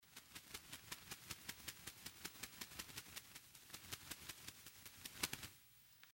На этой странице собраны звуки бабочек и мотыльков – нежные шелесты крыльев, создающие атмосферу летнего сада или лесной поляны.
Звук крыльев мотылька